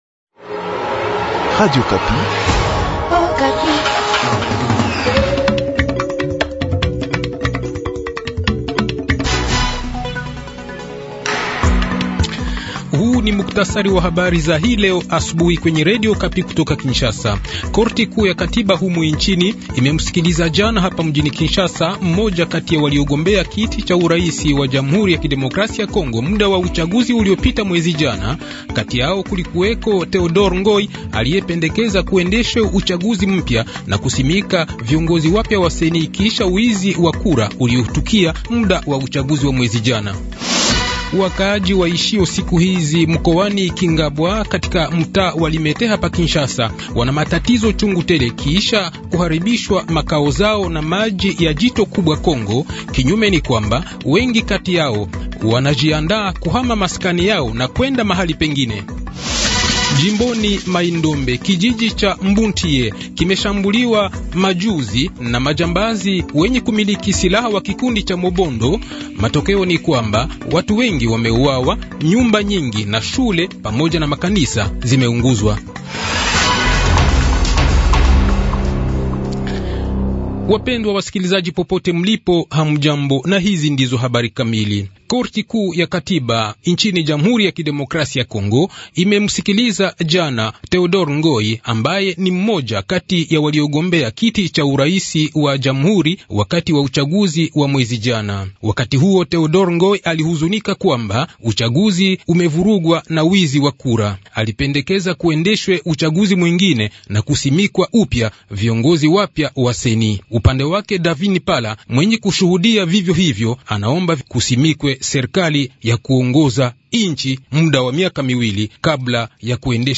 Le journal de 5 h, 9 janvier 2024